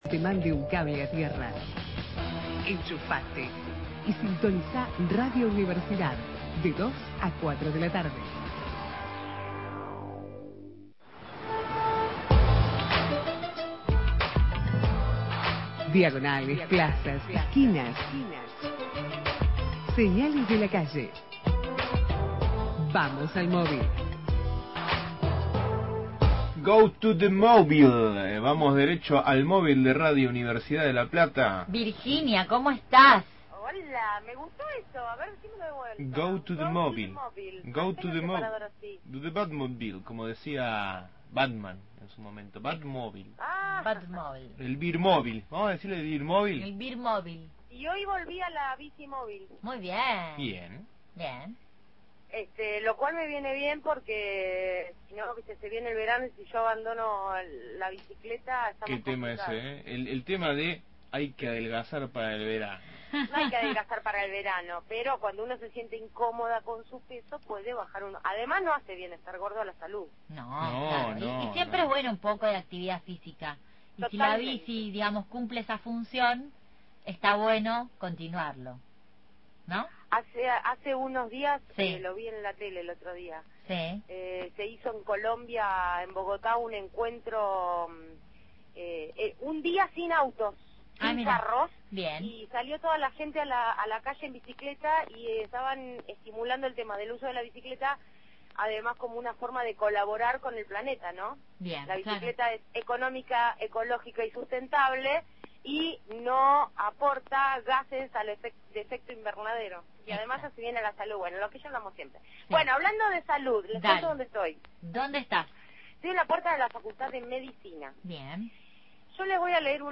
MÓVIL/ Facultad de Medicina – Radio Universidad